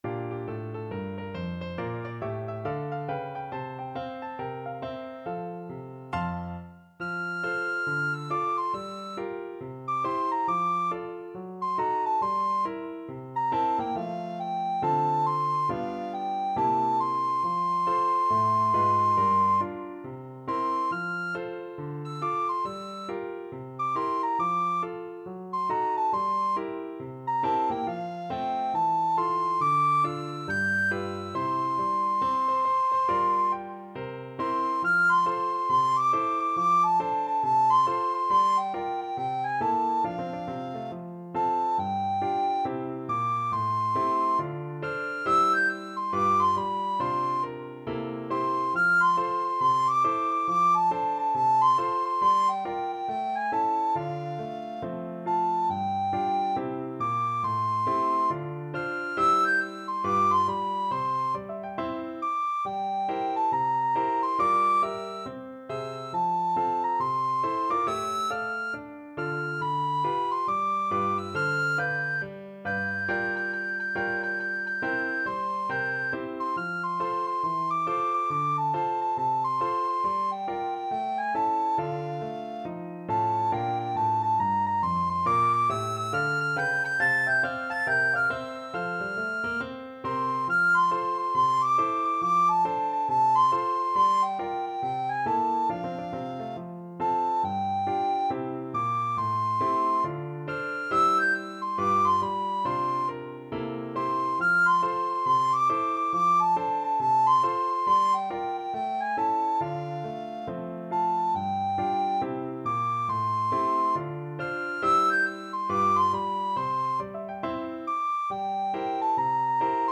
Free Sheet music for Soprano (Descant) Recorder
4/4 (View more 4/4 Music)
With a swing =c.69
Pop (View more Pop Recorder Music)